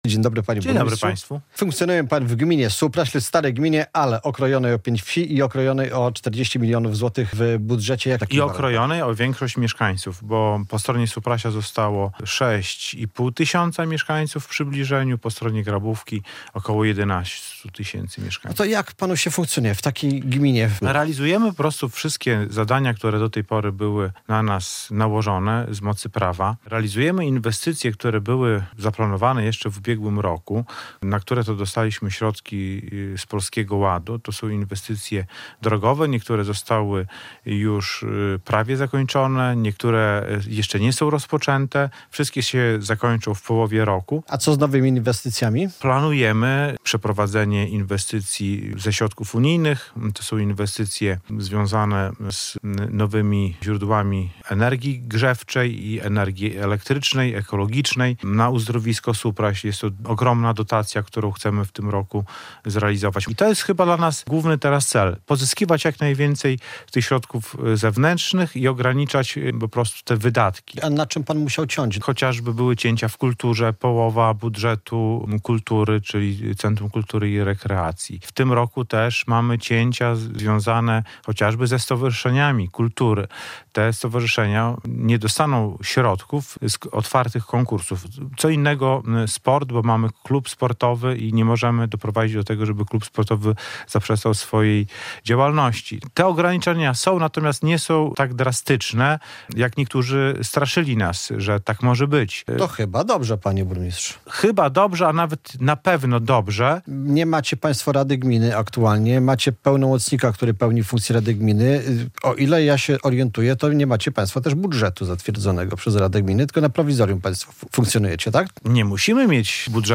Z burmistrzem Supraśla Radosławem Dobrowolskim rozmawia